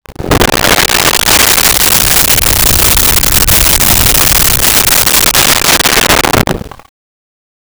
Creature Breath 01
Creature Breath 01.wav